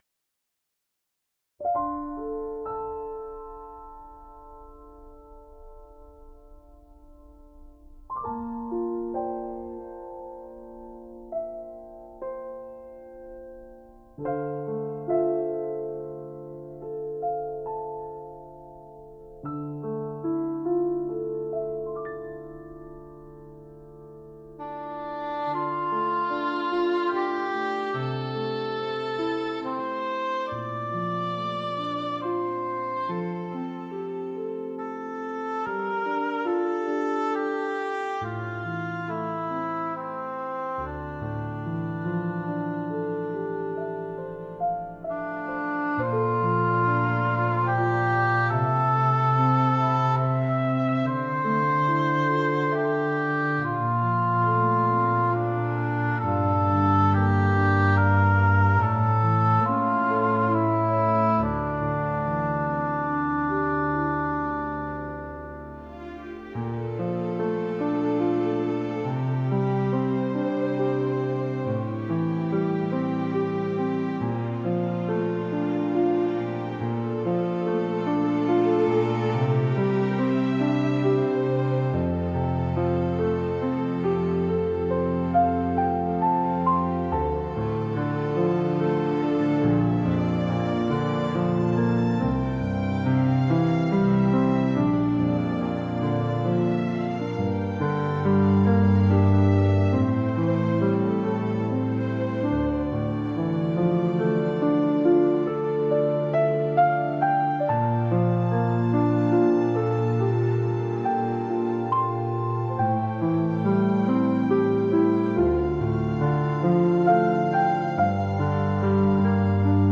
Genre: Orchestral Mood: Dreamy Editor's Choice